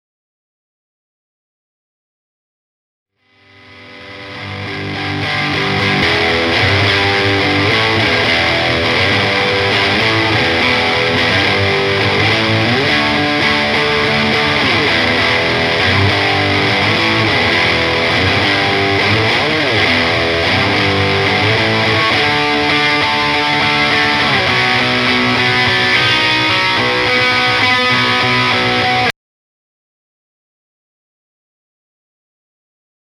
To hear what stacked OD’s sound like, here’s a clip I took out of a song I’ve been working on.
Strat -> Tube Screamer -> Holy Fire -> Mk.4.23 Booster -> Reason SM25
The TS overdrive is set at about 2pm, the Holy Fire’s overdrive is set a 12, with distortion just past 2pm, and the Mk.4.23 booster is dimed for an extra 24db of boost.
This is the raw, unmastered track. It’s really aggressive, but as you can see, it’s not super-compressed.